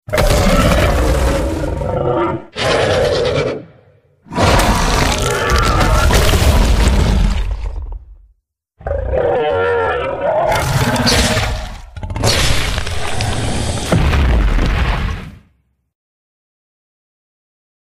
testing out some gore sounds sound effects free download